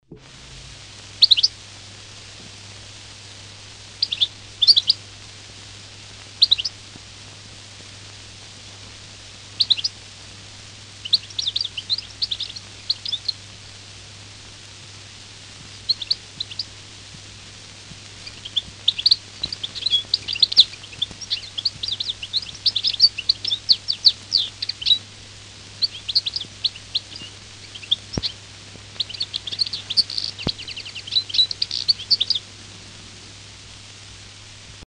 1935 erschienen im Verlag von Hugo Bermühler in Berlin-Licherfelde insgesamt drei Schallplatten mit dem Titel „Gefiederte Meistersänger“, die die Singstimmen von Vögeln dokumentieren.
Der Stieglitz
2401-3-A-Stieglitz.mp3